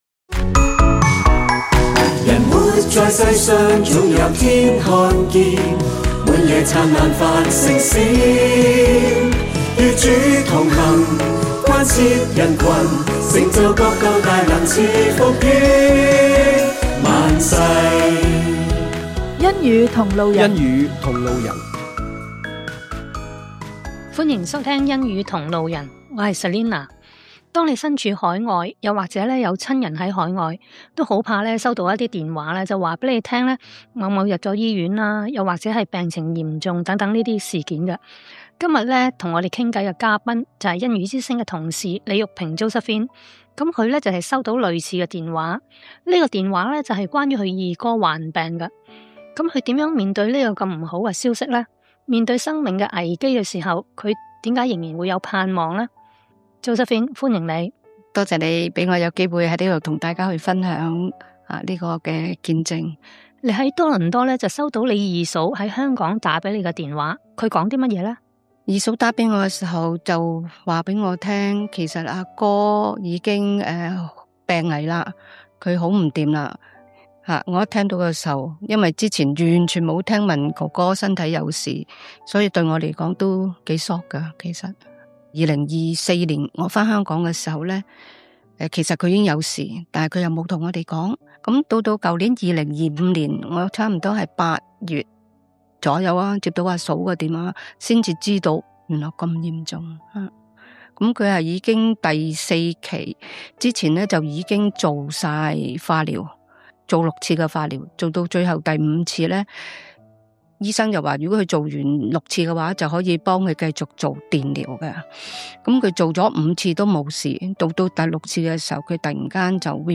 電台見證 【復活節特輯